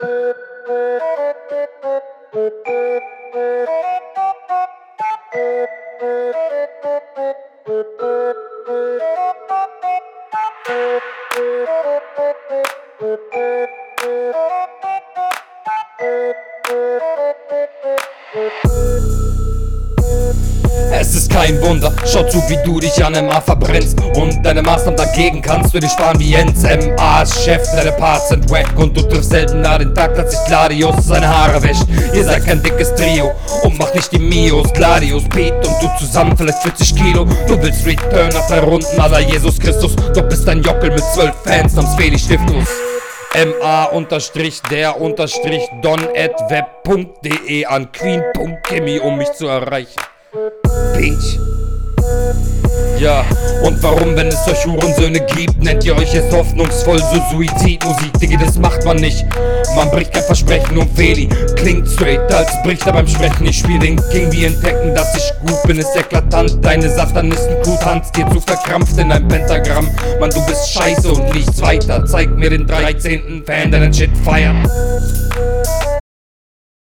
Flowtechnisch stabil, nix zu verspieltes sondern mehr auf Style ausgelegt.